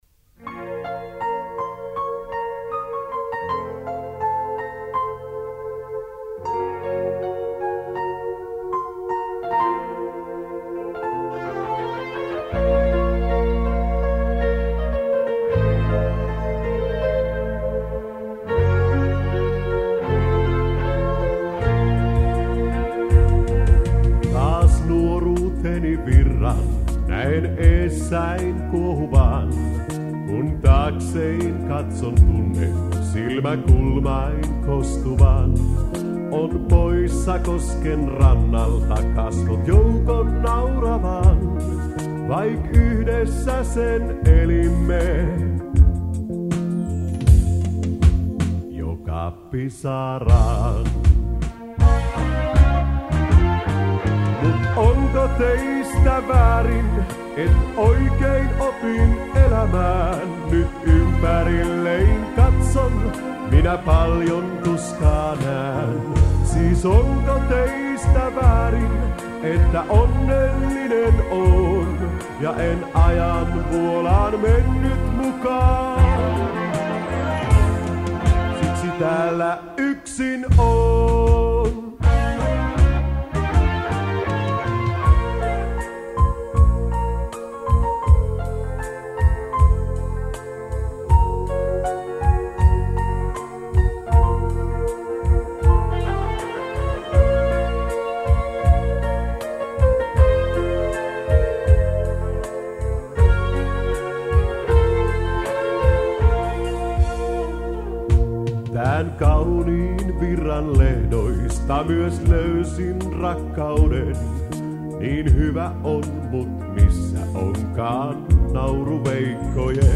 Soitettu kaikki syntikalla.